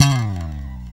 Index of /90_sSampleCDs/Roland L-CD701/BS _Jazz Bass/BS _E.Bass FX